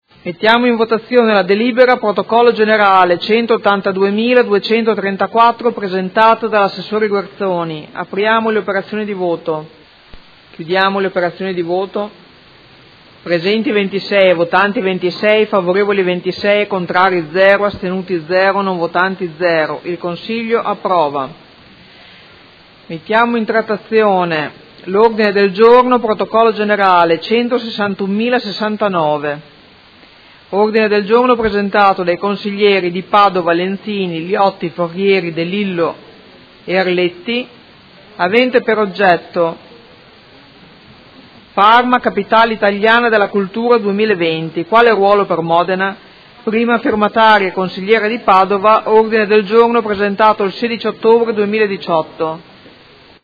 Seduta del 13/12/2018. Mette ai voti proposta di deliberazione: Restituzione da parte della Provincia di Modena dell’immobile di Via Reggianini n. 3